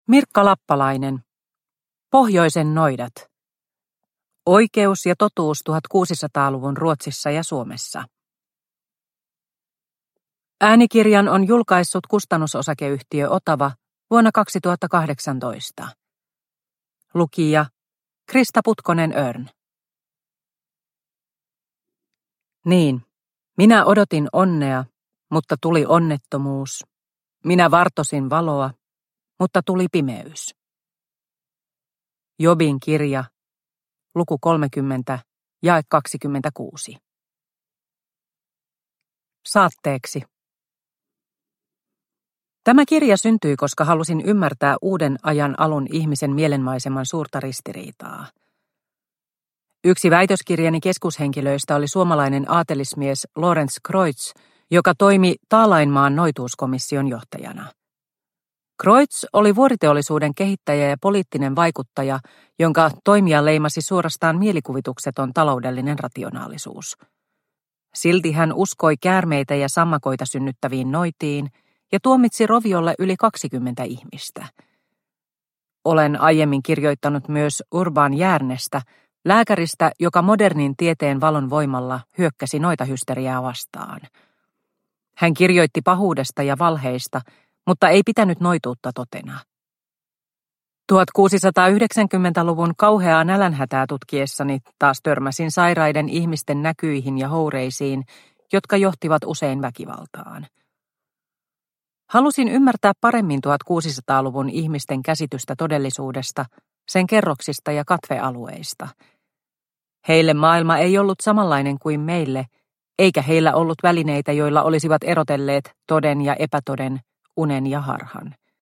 Pohjoisen noidat – Ljudbok – Laddas ner